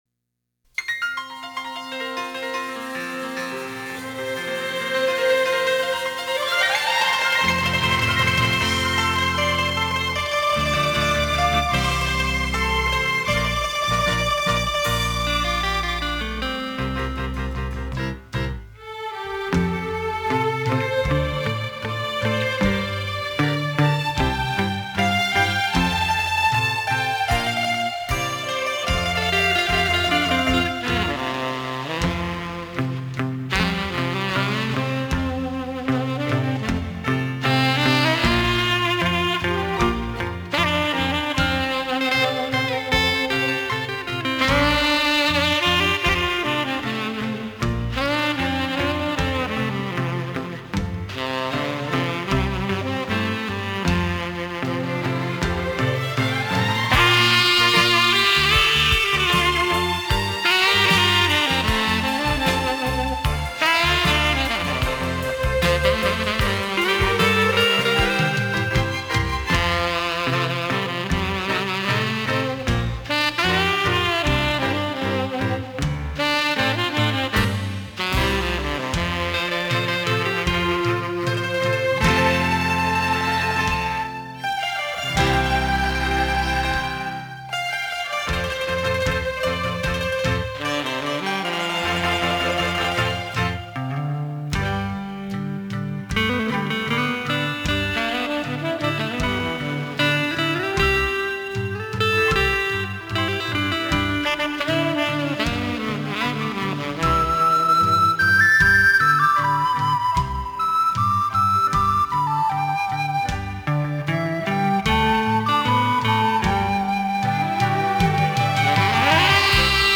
纯音